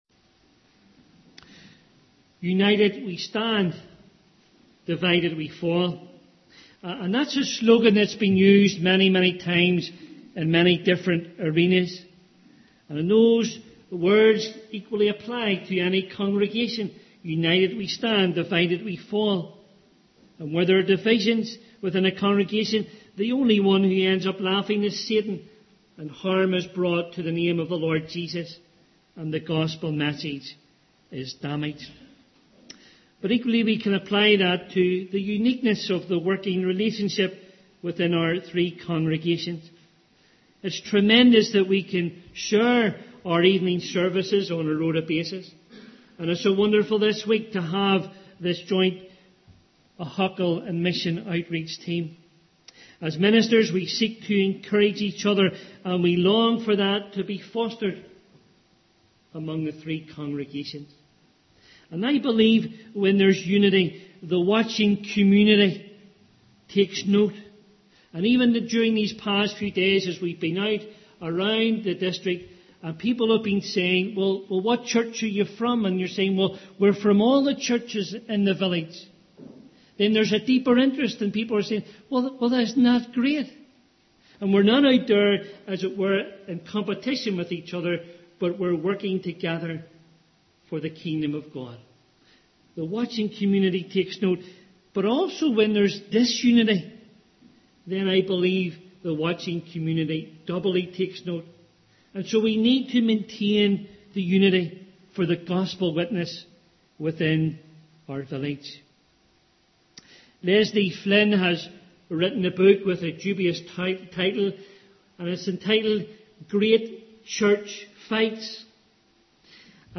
Please note: this sermon was preached before Brookside adopted the English Standard Version as our primary Bible translation, the wording above may differ from what is spoken on the recording.